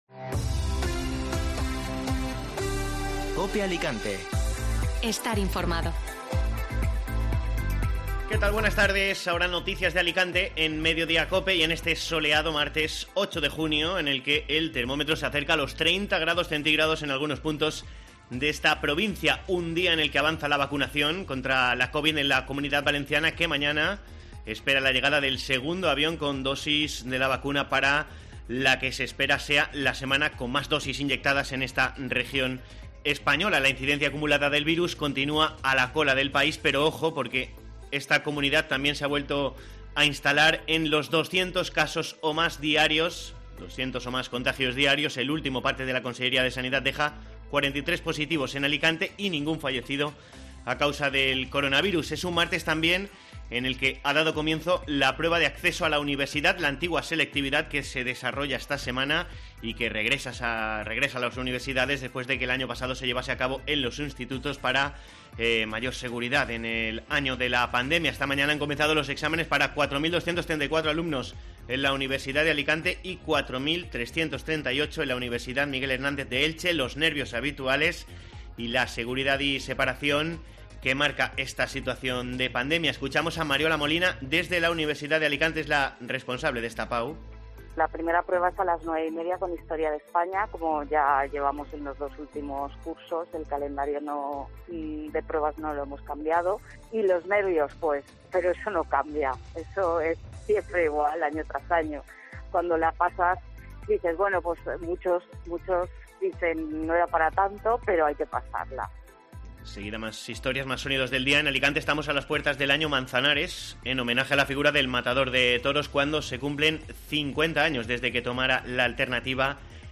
Informativo Mediodía COPE (Martes 8 junio)